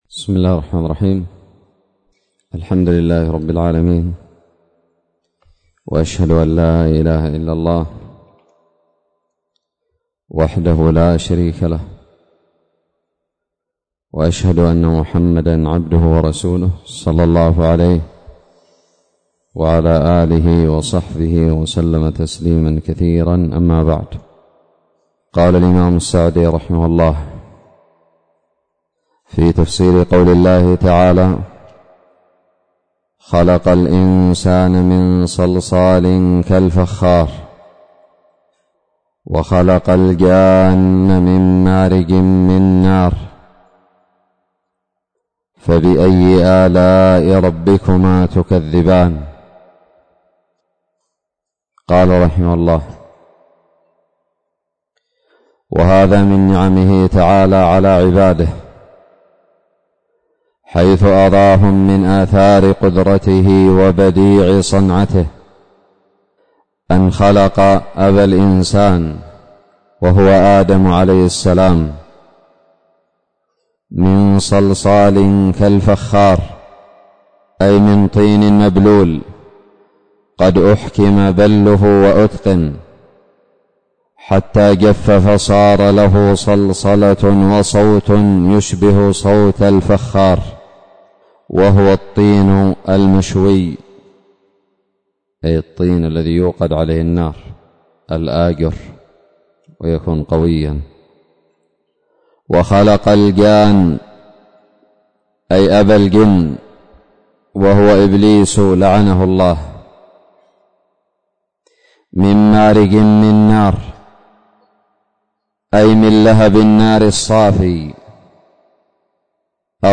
الدرس الثاني من تفسير سورة الرحمن
ألقيت بدار الحديث السلفية للعلوم الشرعية بالضالع